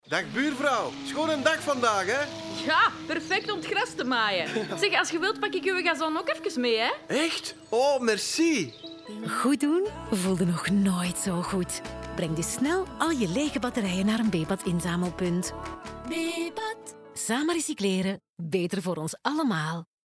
Sound Production & Sound Design: La Vita Studios
250324-Bebat-radio-mix-OLA--23LUFS-tuin-NL-20.mp3